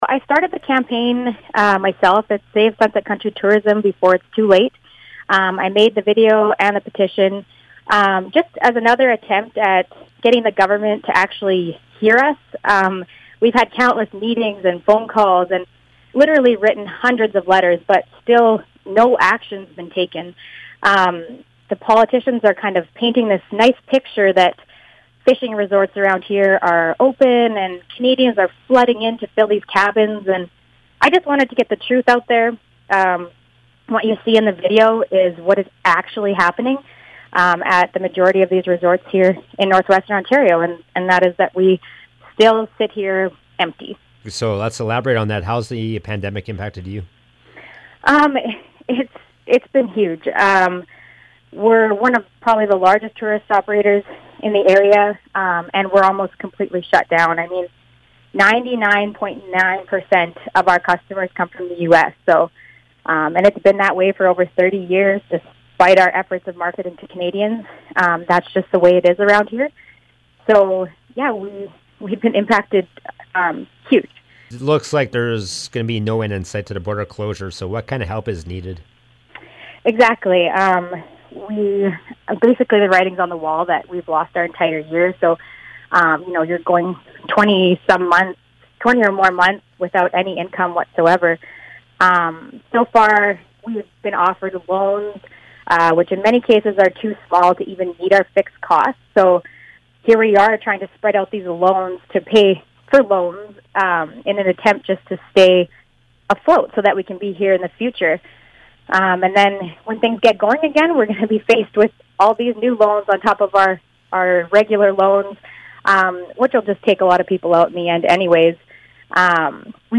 To hear the full interview and to view the emotional video on the importance of tourism to Sunset Country, visit the links below. https